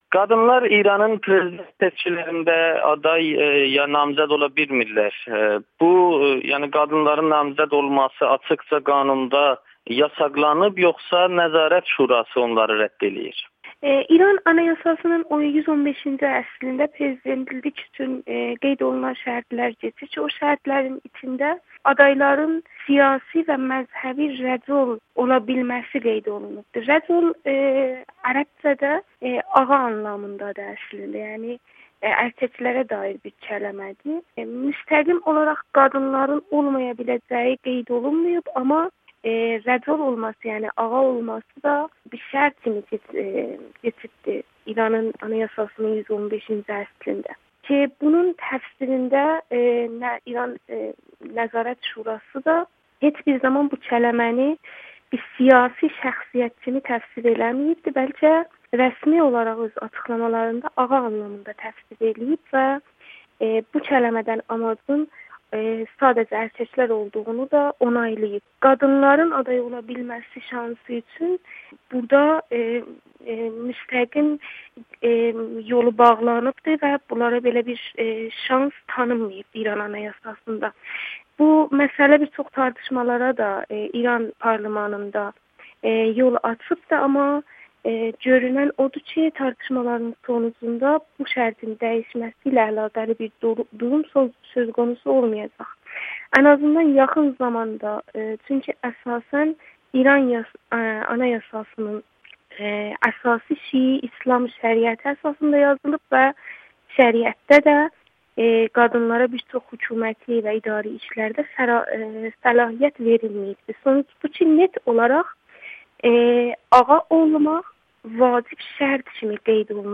Salmaslı milli fəal Amerikanın Səsi ilə müsahibədə İranda qadınların durumundan danışıb